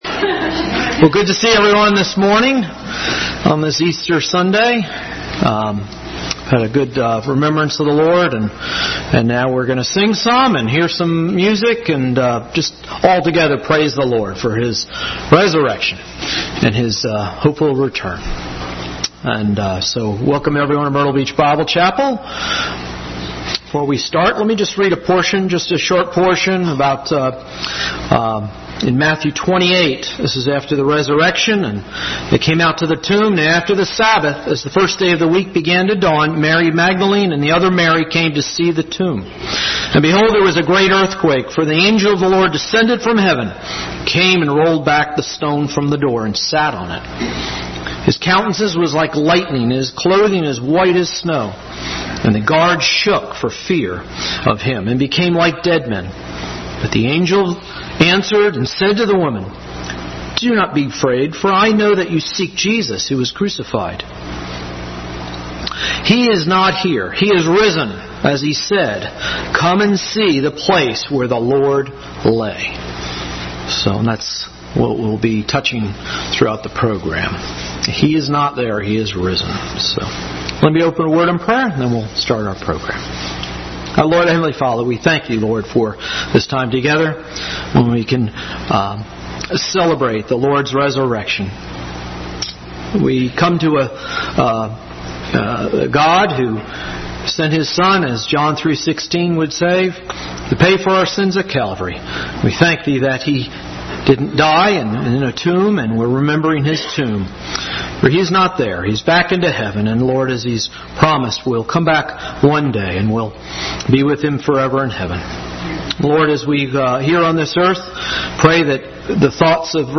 | Special Easter Service April 21, 2019.
Reurrection-Celebration-Easter-Service.mp3